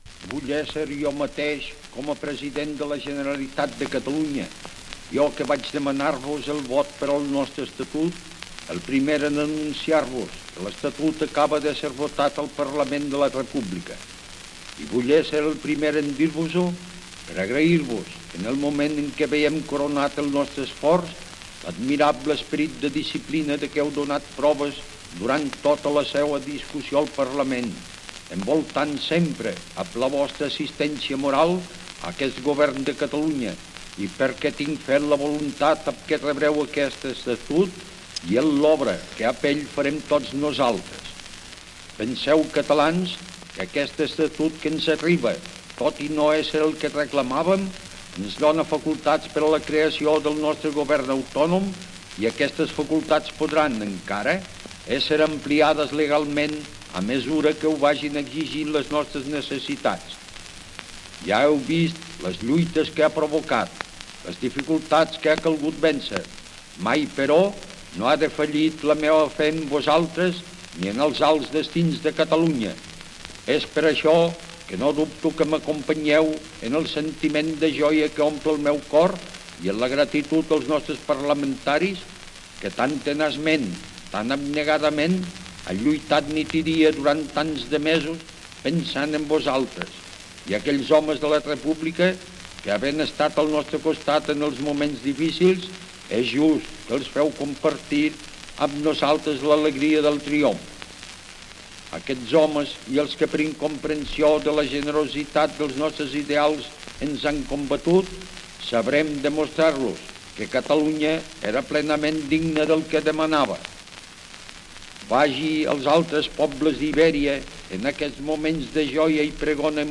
El president de la Generalitat Francesc Macià anuncia que l'Estatut d'Autonomia de Catalunya ha estat aprovat aquell dia (9 de setembre de 1932) per les Corts espanyoles
Informatiu